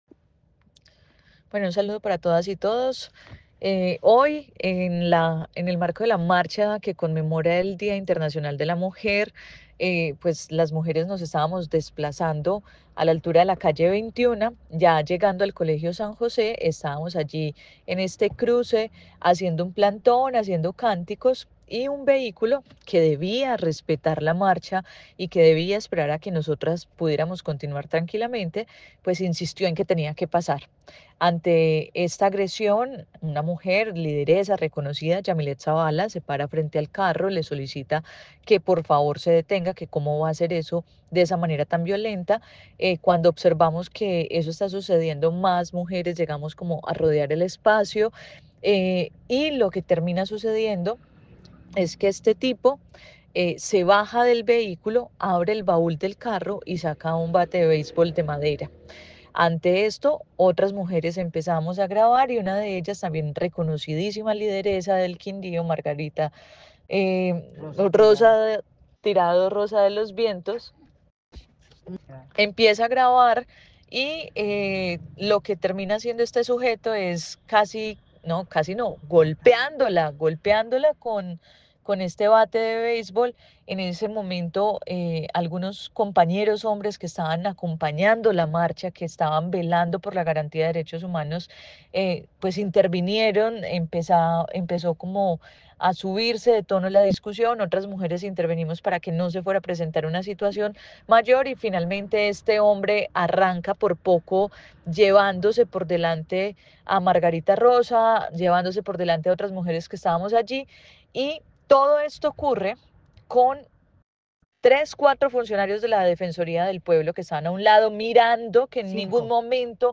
Jessica Obando, diputada del Quindío